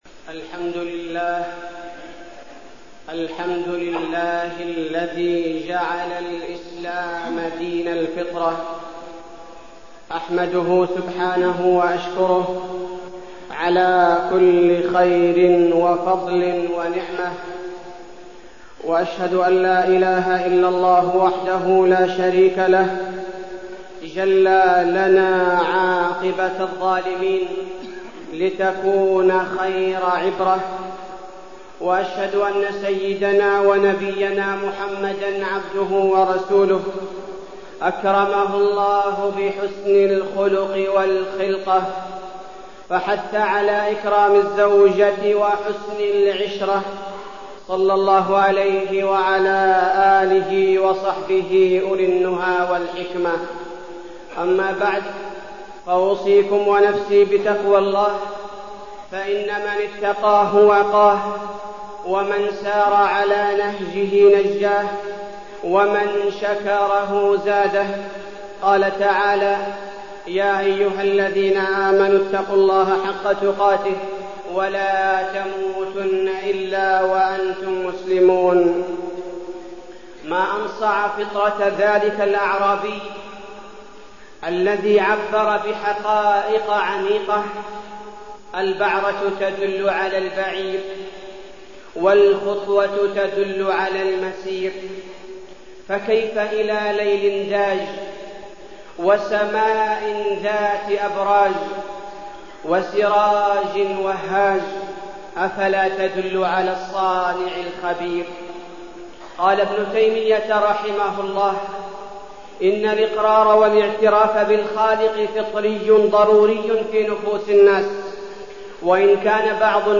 تاريخ النشر ٢٦ شوال ١٤١٩ هـ المكان: المسجد النبوي الشيخ: فضيلة الشيخ عبدالباري الثبيتي فضيلة الشيخ عبدالباري الثبيتي الفطرة